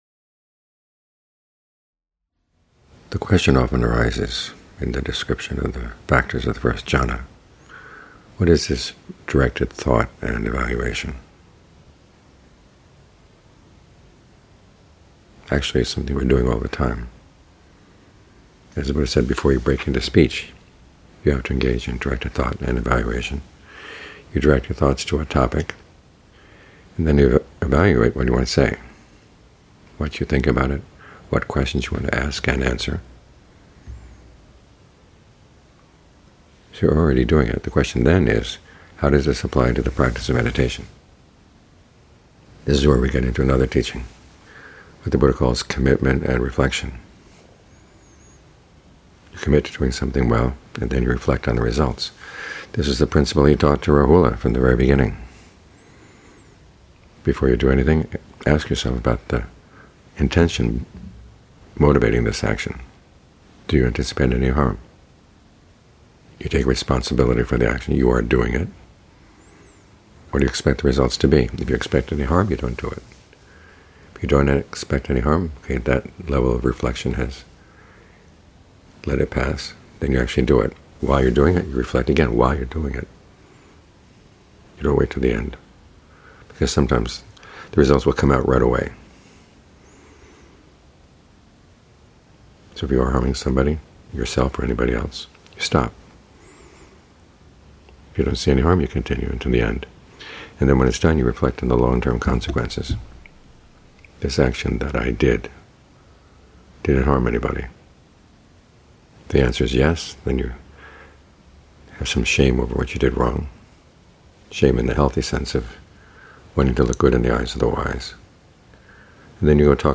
Evening Talks